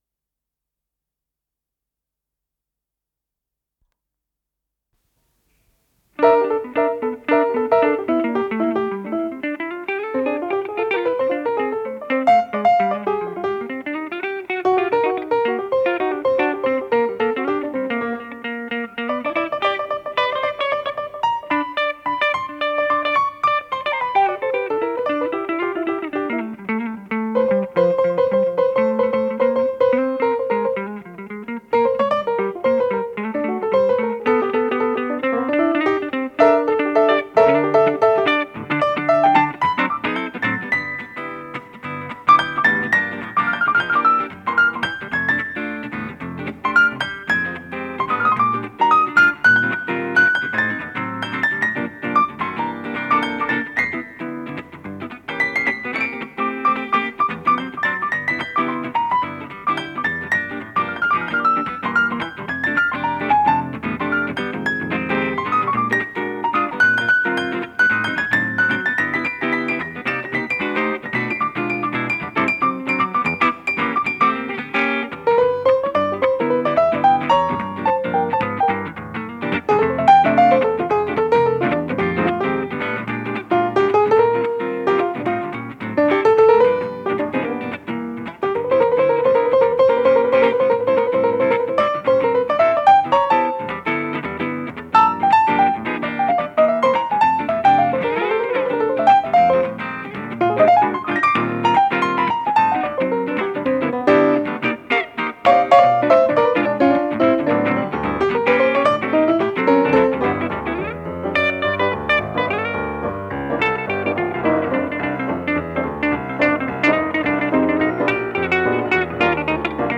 фортепиано
электрогитара
Скорость ленты38 см/с
ВариантДубль моно